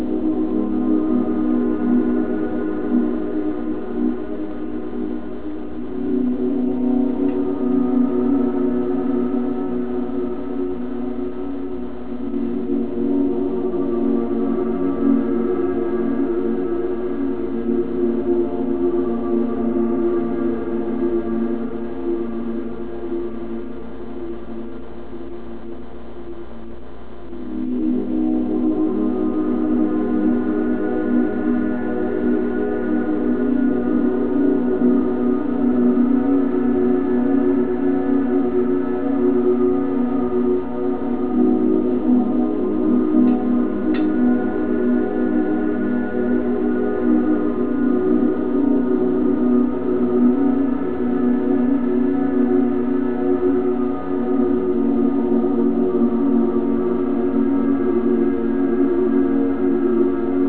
With Reverse Speech Technology